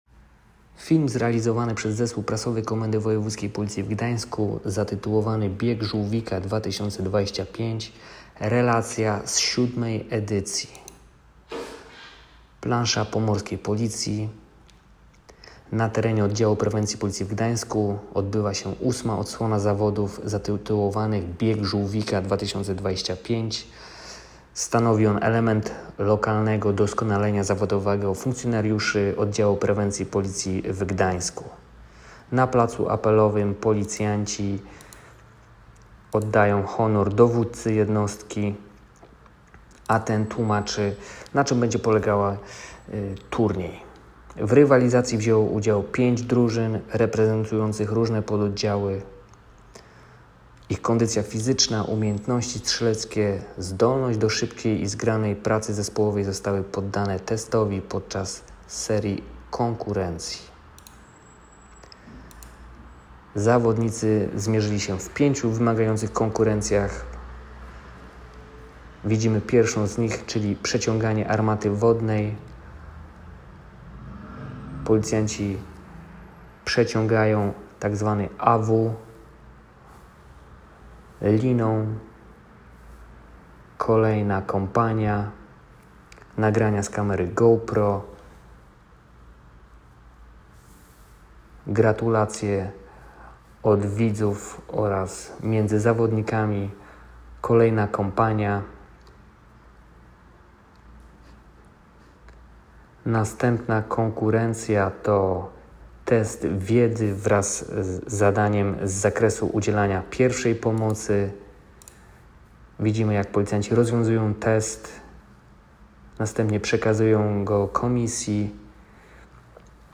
Nagranie audio Audiodyskrypcja